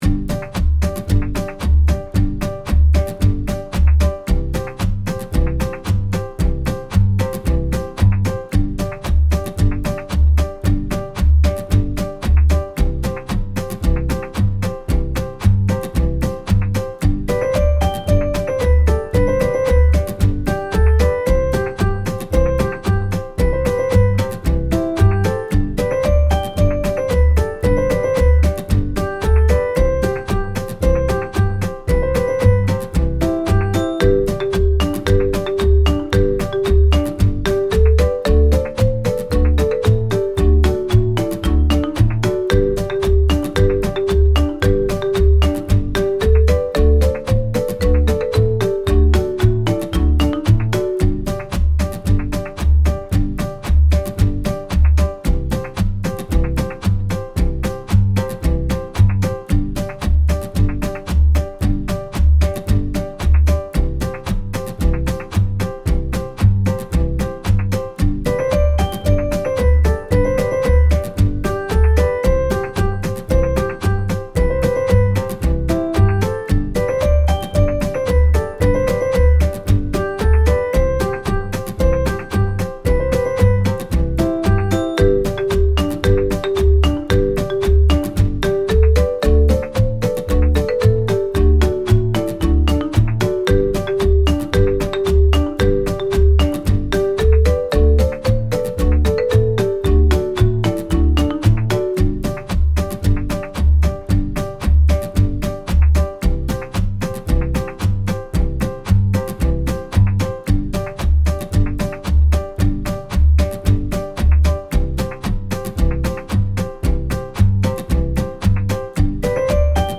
かわいい冒険の旅を感じさせるBGM
アコースティック, ポップ 2:34 ダウンロード